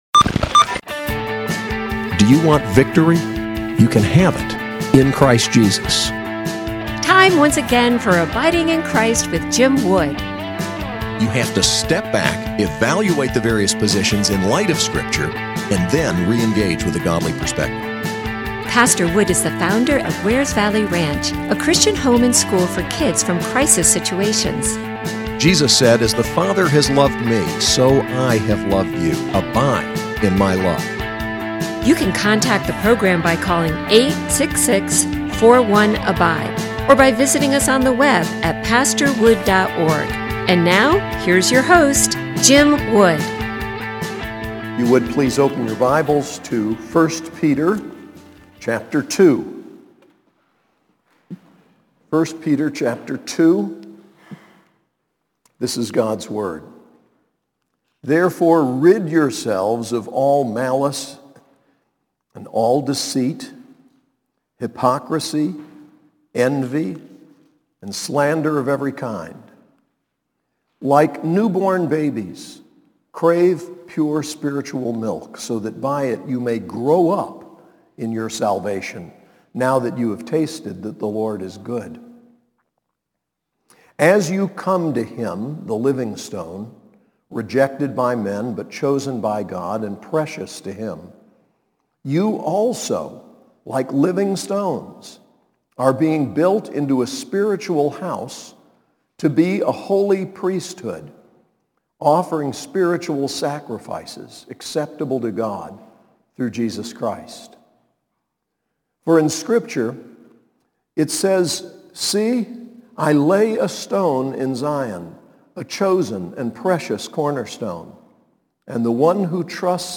SAS Chapel